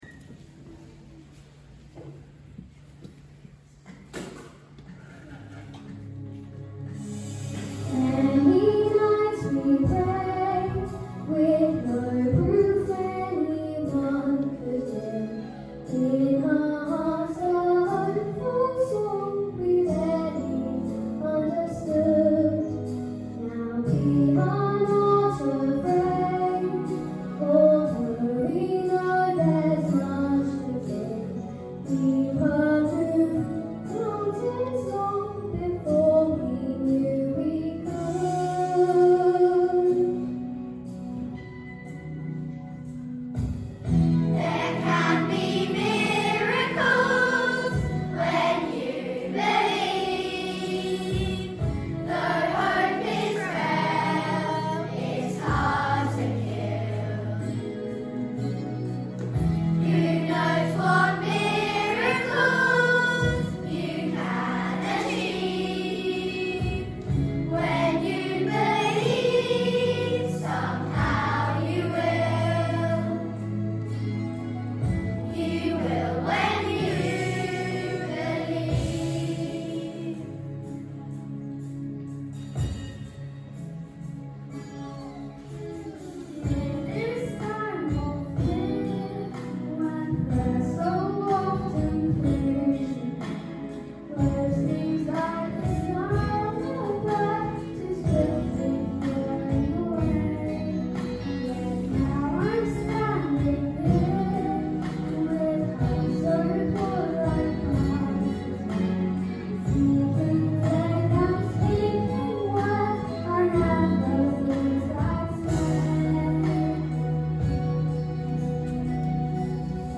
Y4-6 Choir - When You Believe